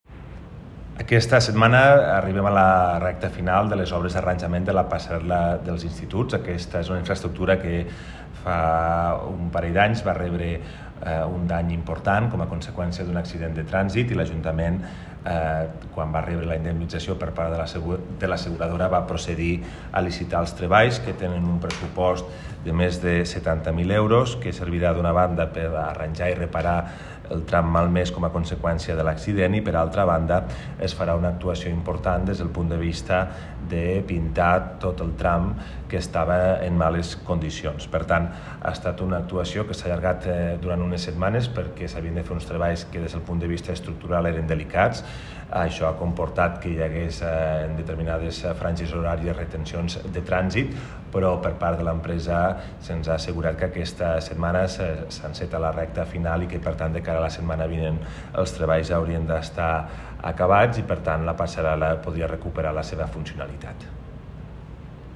Decarregar imatge original Fitxers relacionats Tall de veu del primer tinent d'alcalde Toni Postius sobre la reparació de la Passarel·la.
tall-de-veu-del-primer-tinent-dalcalde-toni-postius-sobre-la-reparacio-de-la-passarel-la